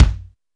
KICK 900.WAV